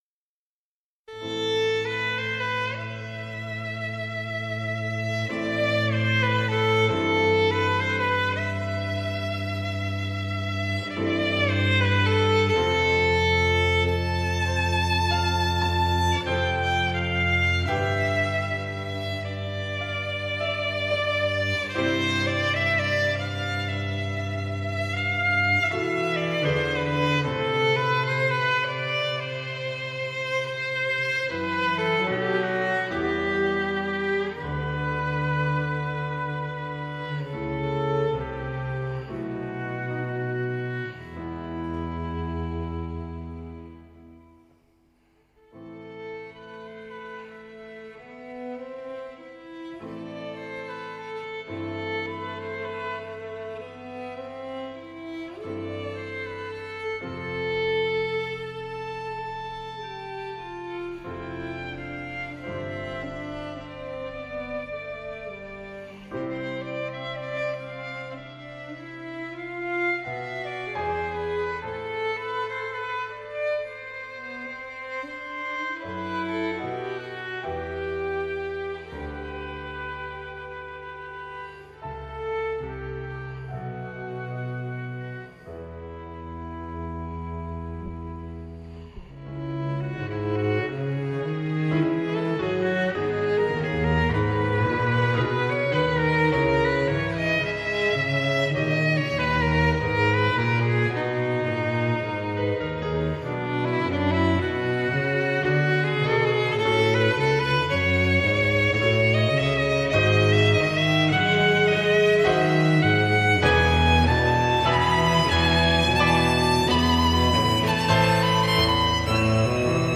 скрипка и фортепиано